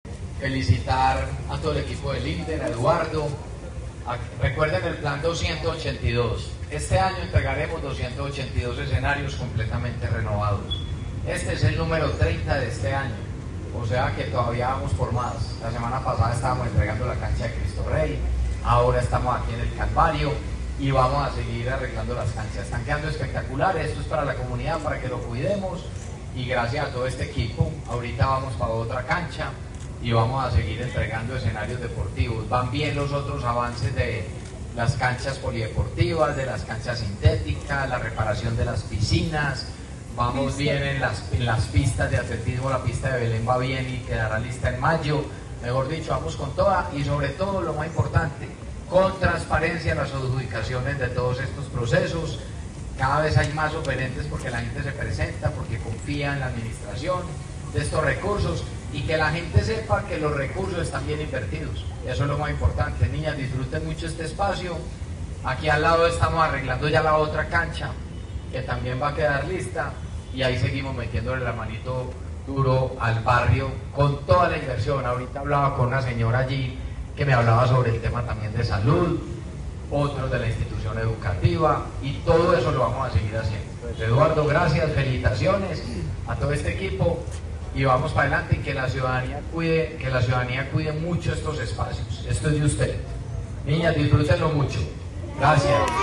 Palabras de Federico Gutiérrez Zuluaga, alcalde de Medellín
Audio-Alcalde-escenarios-deportivos-renovados-Manrique-y-Aranjuez-01.mp3